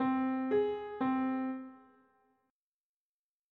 Minor 6th (m6th)
A Minor 6th is the interval between C and eight half steps above to Ab.